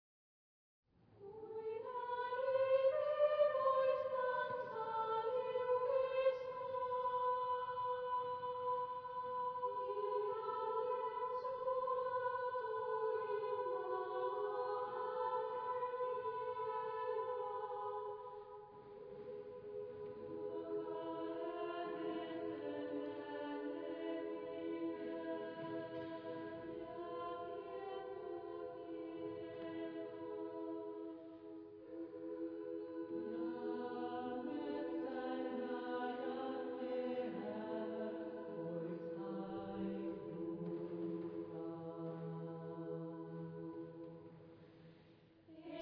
Genre-Style-Forme : Profane
Type de choeur : SSAA  (4 voix égales de femmes )